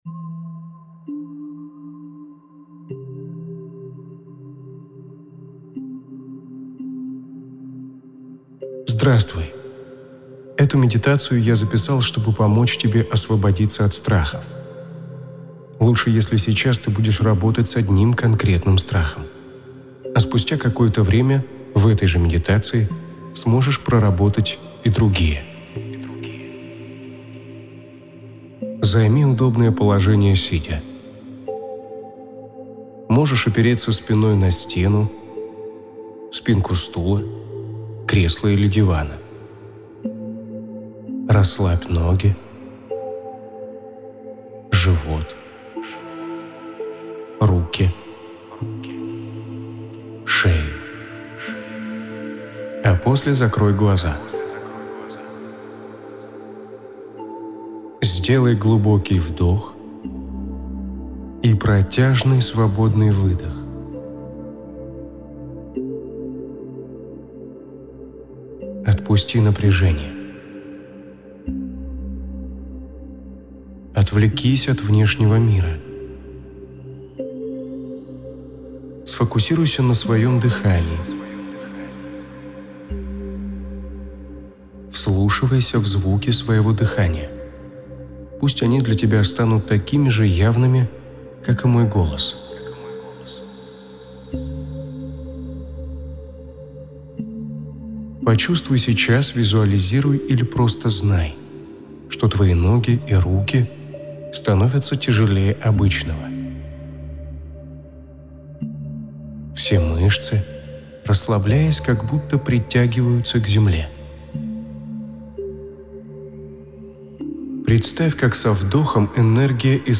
Уникальные аудио уроки и практики по медитации преодоления тревоги и страха
meditaciya-preodolenie-strahov-praktika.mp3